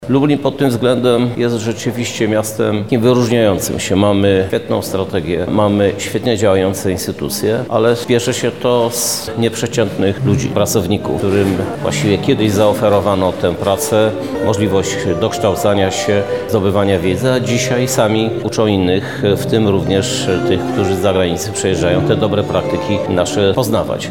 Odbyły się miejskie obchody Dnia Pracownika Socjalnego wraz z wręczeniem wyróżnień dla osób szczególnie zaangażowanych.
Na temat działań pracowników socjalnych miasta mówi Prezydent Lublina dr Krzysztof Żuk: